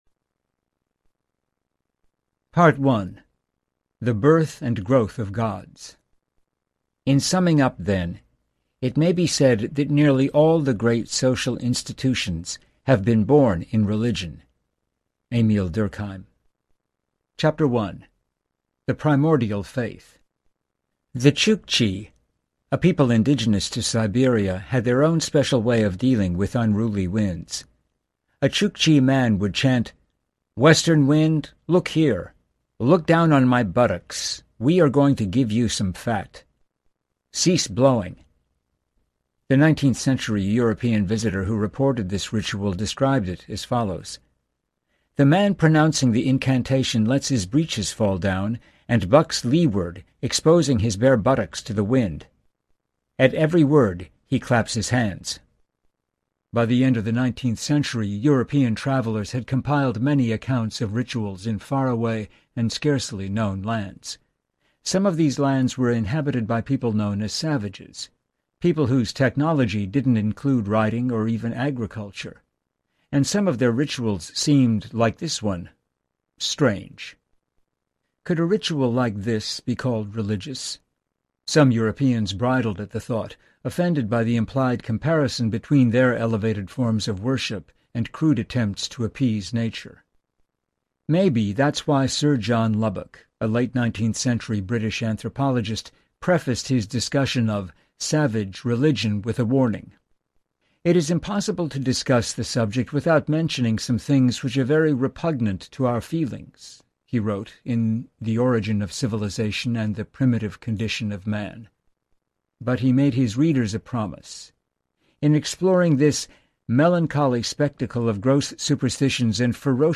The Evolution of God (Back Bay Readers’ Pick) Audiobook
Narrator